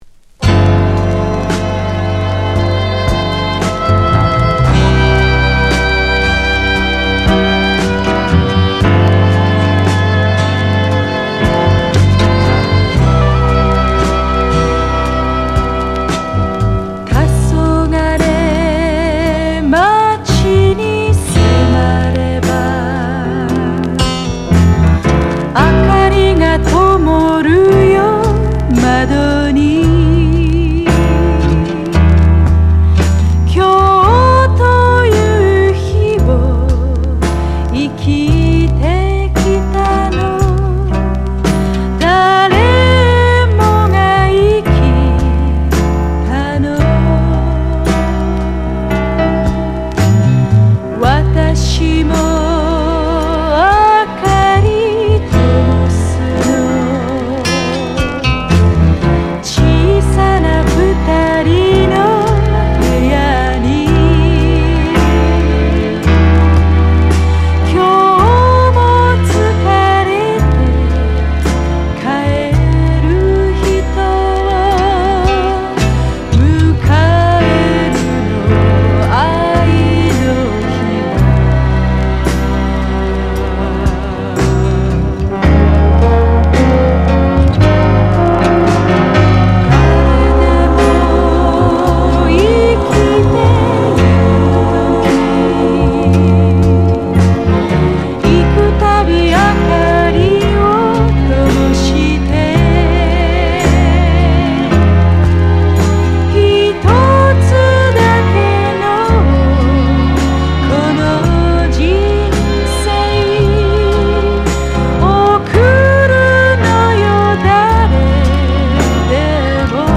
参加ミュージシャン